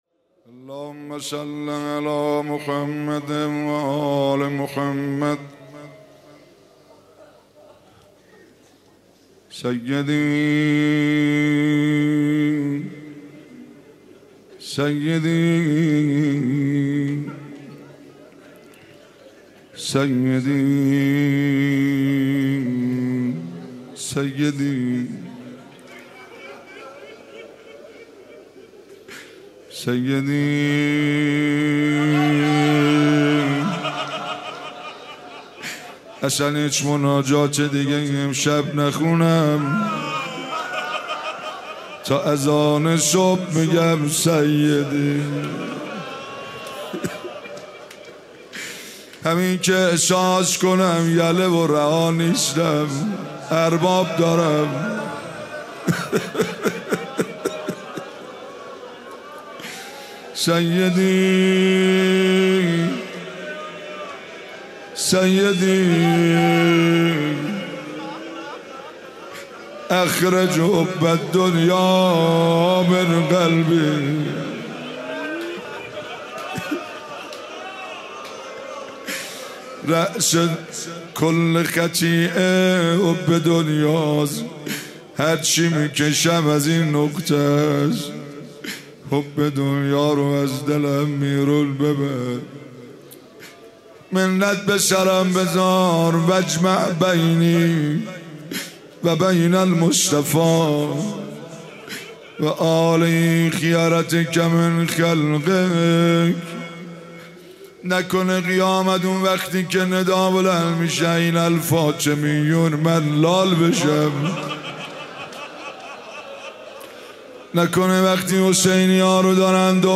12 فروردین 97 - شیراز - مناجات - سیدی سیدی
مراسم اعتکاف